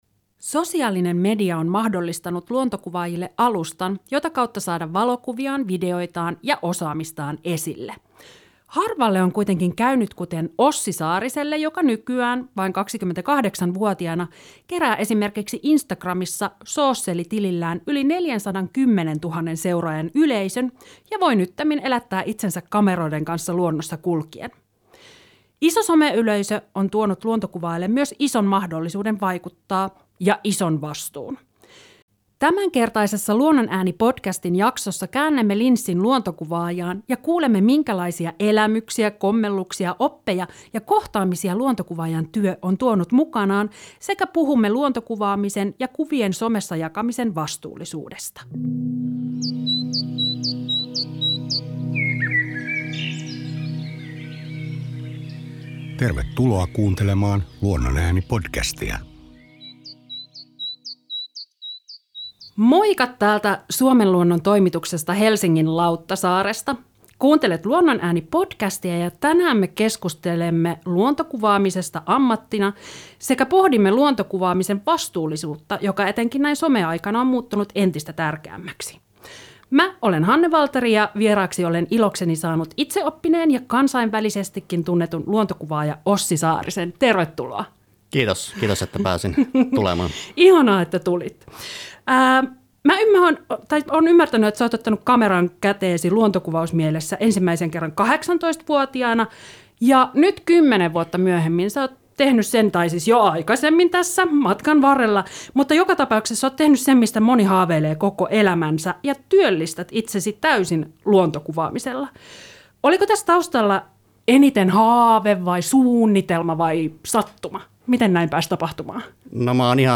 Suomen Luonnon studioon